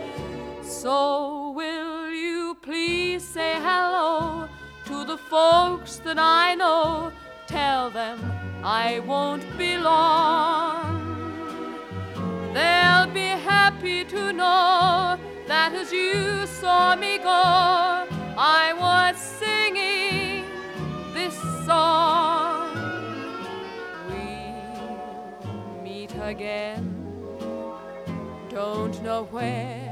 • Vocal Pop